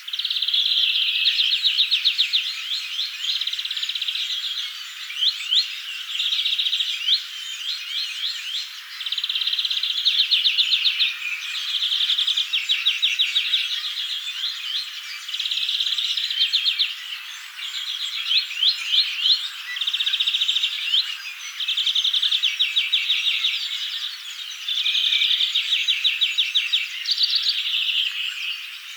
erikoisesti laulava viherpeippo,
hiukan tiaismaisia "väliääniä" laulussa
erikoisesti_laulava_viherpeippo_erikoisia_ehka_vahan_tiaismaisia_tai_jotain_aania.mp3